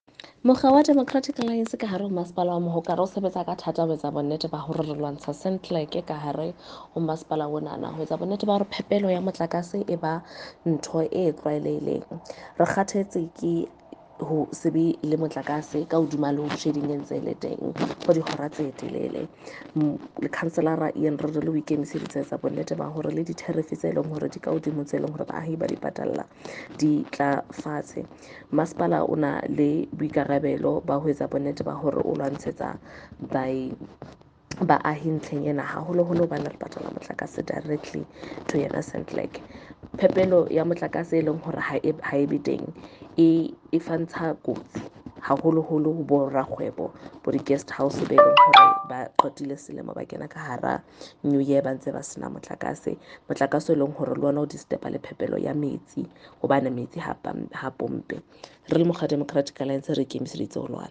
Sesotho soundbites by Karabo Khakhau MP.